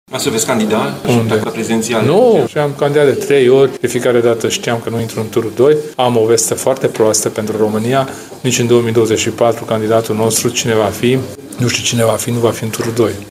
Președintele UDMR, Kelemen Hunor, a mai anunțat, că nu va candida la alegerile prezidențiale din 2024.